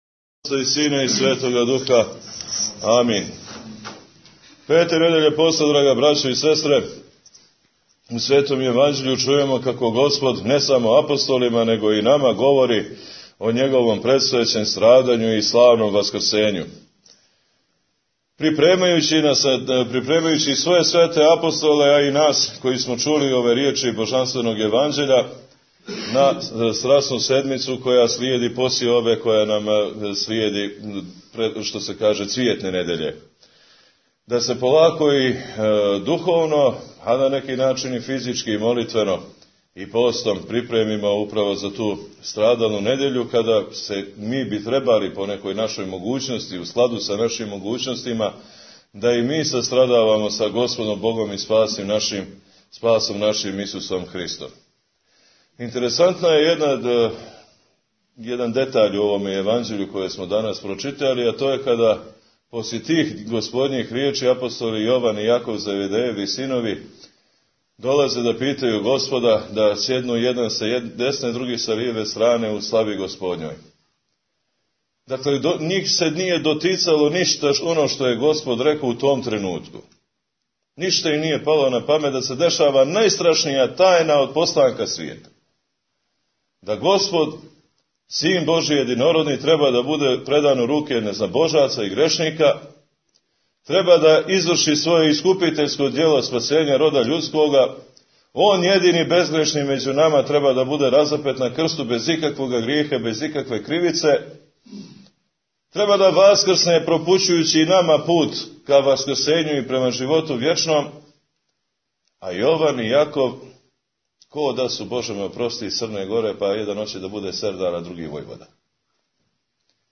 Бесједе | Радио Светигора